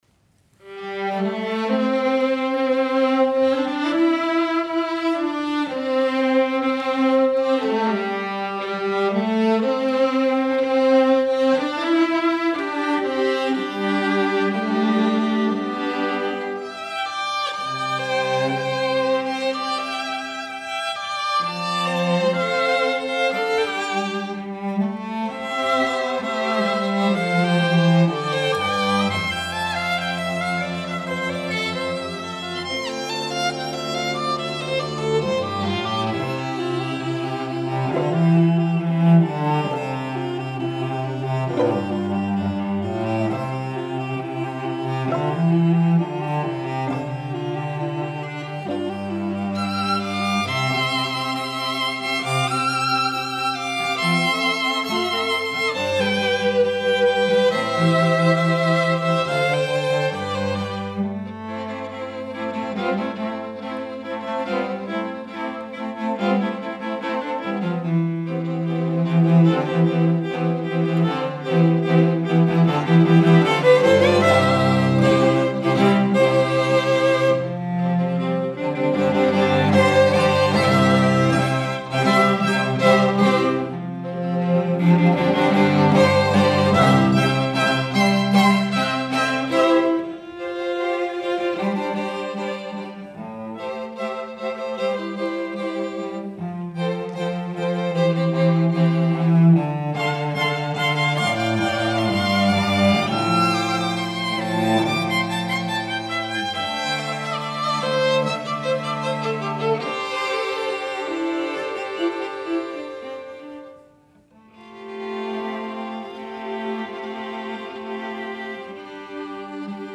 for String Quartet (2014)